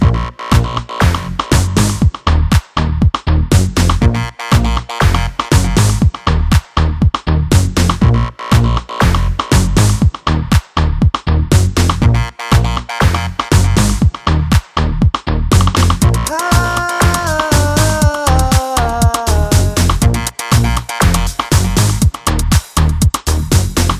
no Backing Vocals Dance 4:14 Buy £1.50